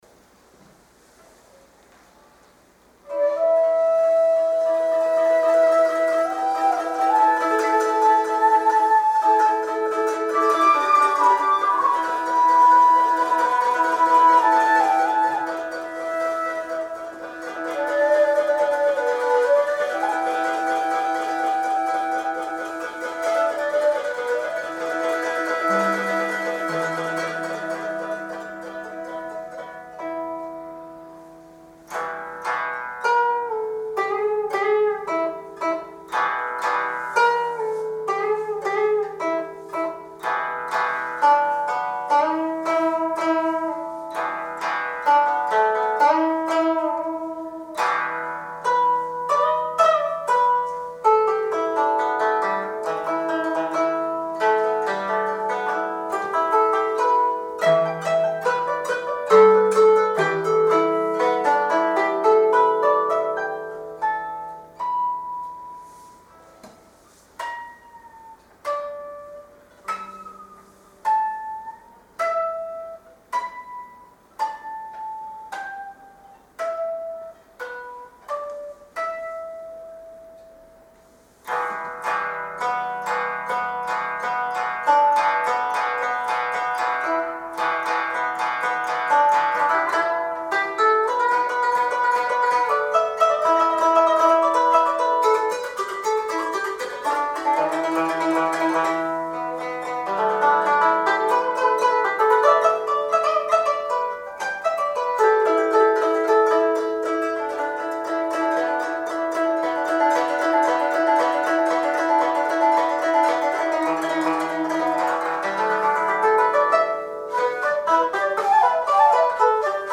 尺八は自然の音に近いということで、鳥の鳴き声も真似できるという話をしました。
途中にタンギングの箇所も出てくるので必要悪でしょうか、やや歯切れのよい一尺六寸管を使用して吹きました。